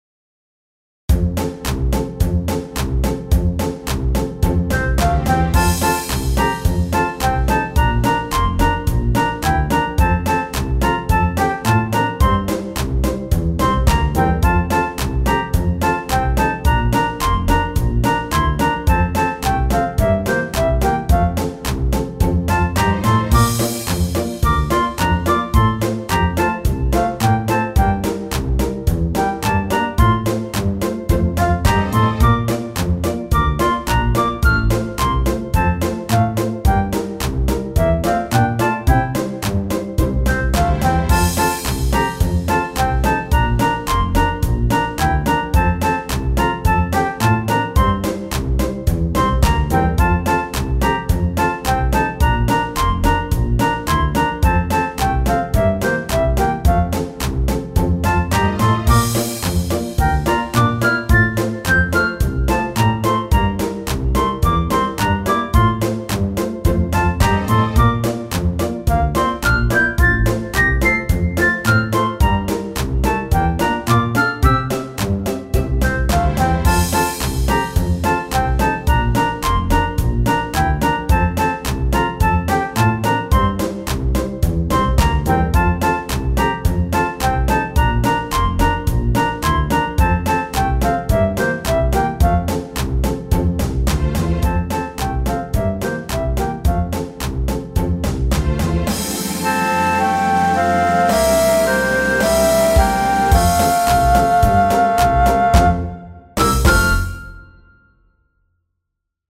BGM
アップテンポエレクトロニカ明るい穏やか